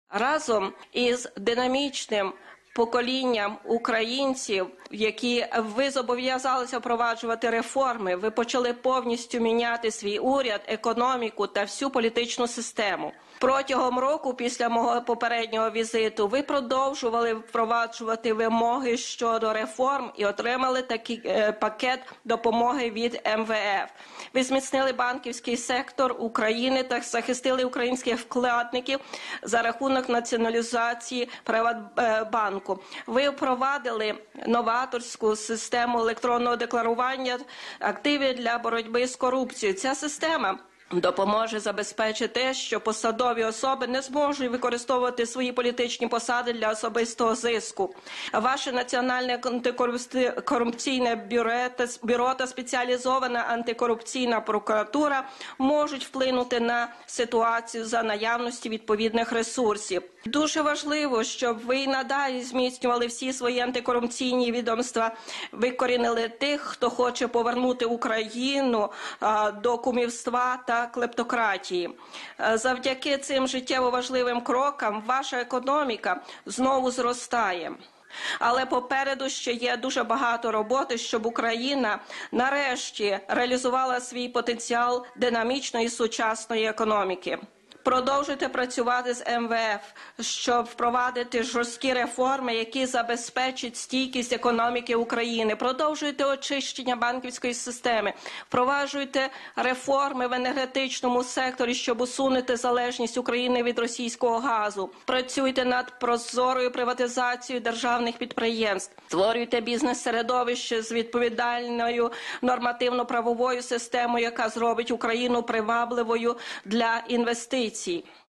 Последний визит Джо Байдена в Украину в качестве вице-президента США. Что прозвучало на совместной с президентом Порошенко пресс-конференции?
Послушаем его прямую речь.